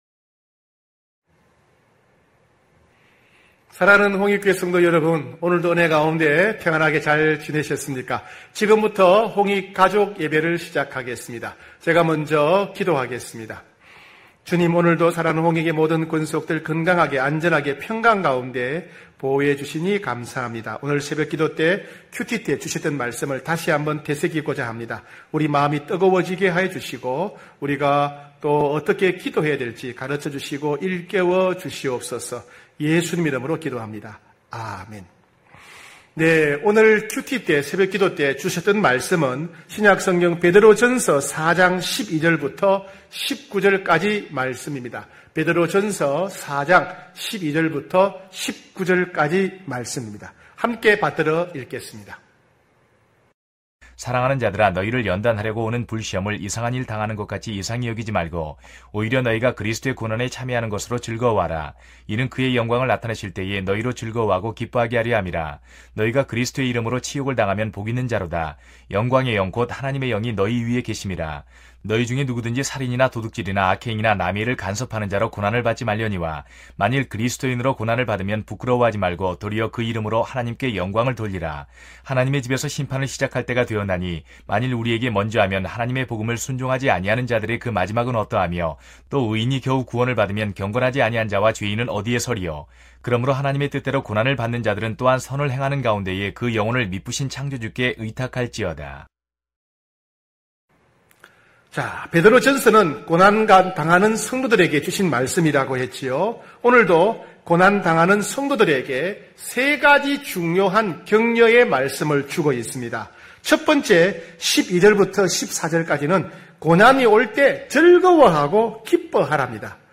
9시홍익가족예배(7월8일).mp3